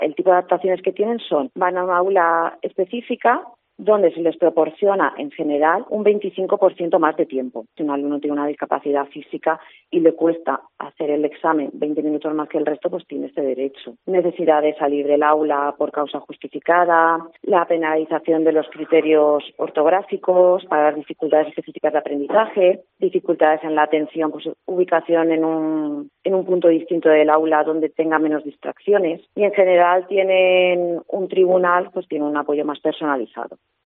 orientadora